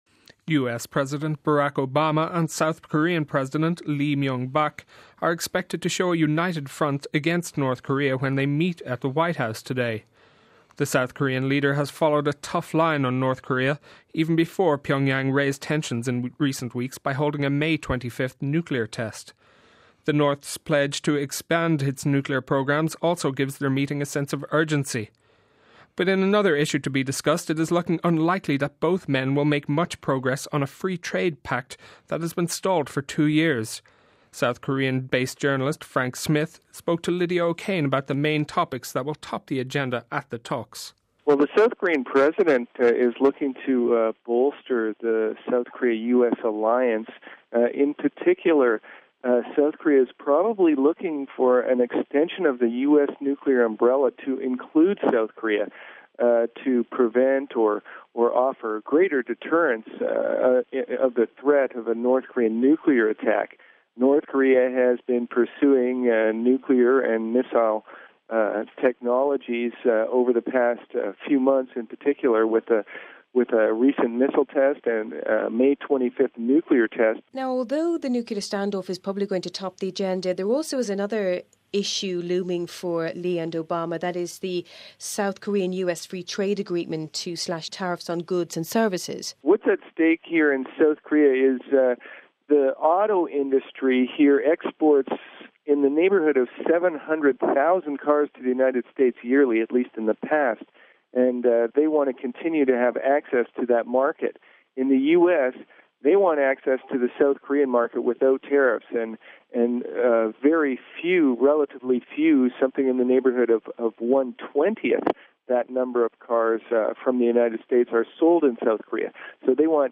(16 Jun 09 - RV) President Barack Obama and South Korean President Lee Myung-bak are expected to show a united front against North Korea when they meet Tuesday at the White House. South Korean based Journalist